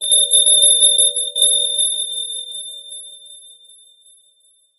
その愛らしさと鮮やかな高音は、まるで魔法のように心をときめかせます。